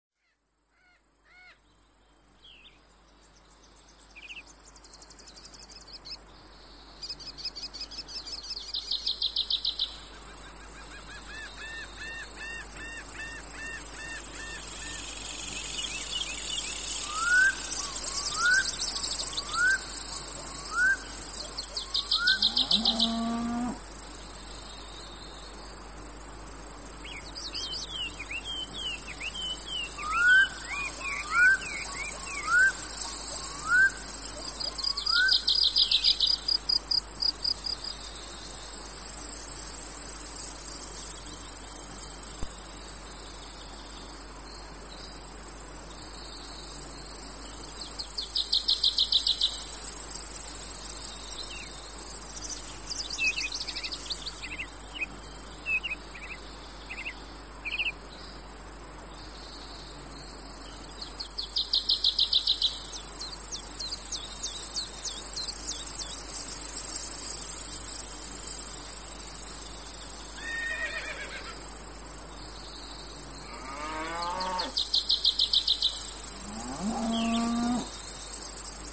Nature Sounds For Sleep (SUMMER)
Relax and enjoy the Summer sounds of nature while you rest or focus.
Nature sounds are recorded & designed to help people sleep, allowing you to relax and enjoy the sounds of nature while you rest or focus with no adverts or interruptions.
Perfect for their masking effects, they are also helpful for people suffering with tinnitus.